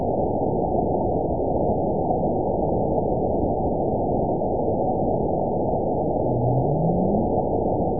event 920072 date 02/21/24 time 21:01:28 GMT (1 year, 3 months ago) score 9.70 location TSS-AB05 detected by nrw target species NRW annotations +NRW Spectrogram: Frequency (kHz) vs. Time (s) audio not available .wav